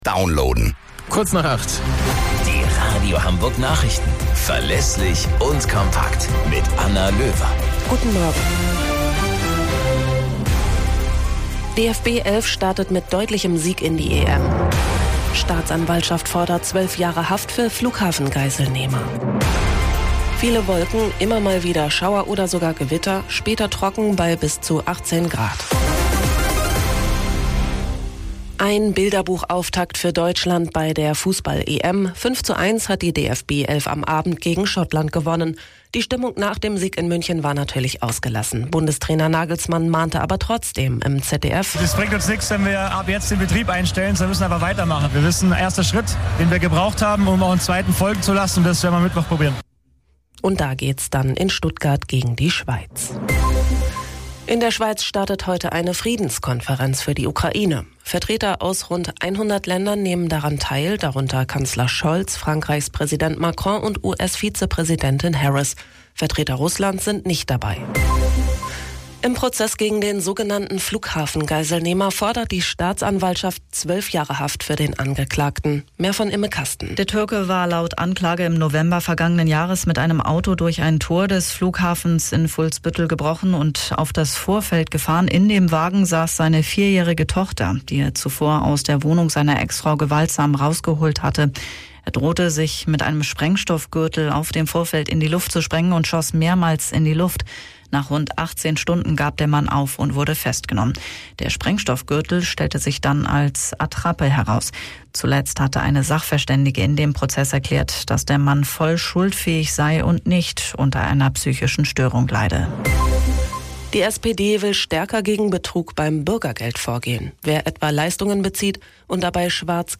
Radio Hamburg Nachrichten vom 15.06.2024 um 15 Uhr - 15.06.2024